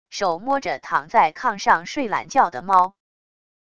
手摸着躺在炕上睡懒觉的猫wav音频